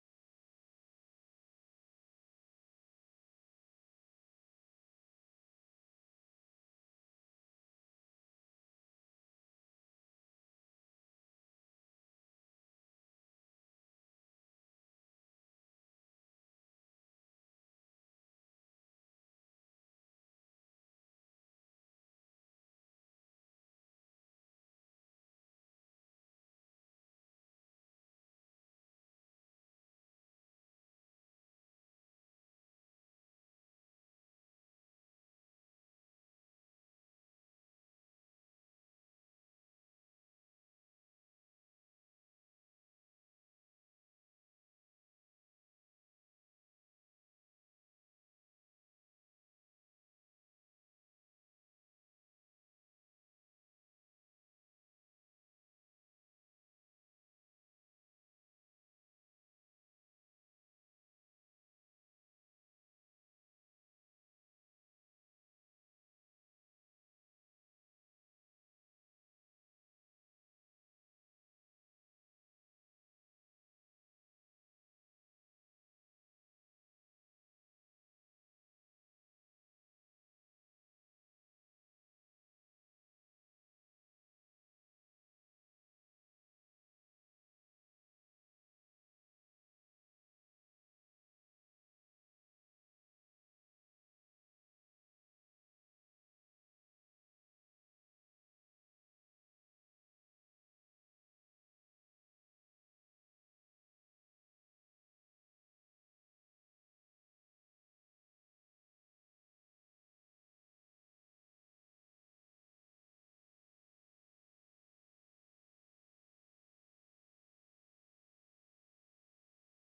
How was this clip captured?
The Board Meeting will be held in the CHS IMC.